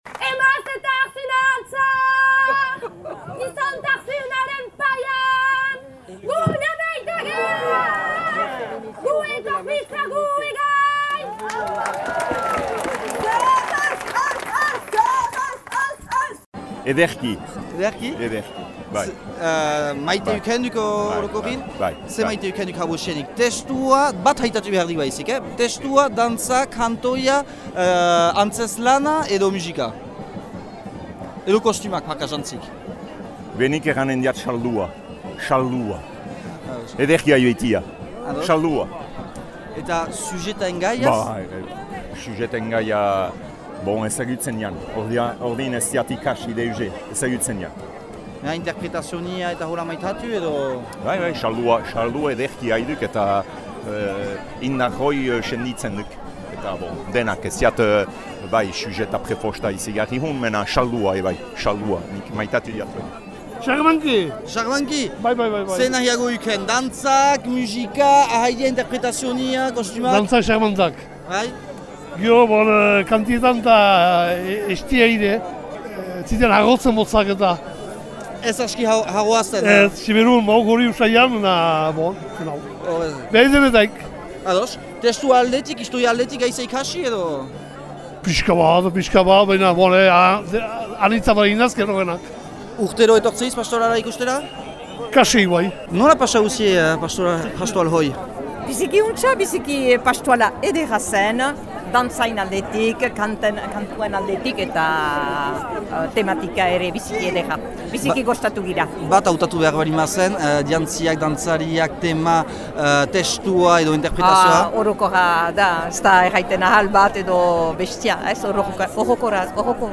Huntan ere bai 2200 leküak beterik ziren eta honekin markak oro hautse dütü Muskildiko herriak 6500 pertsona goiti joan beitira horren ikustera.
simone veil erreportaje osoa.mp3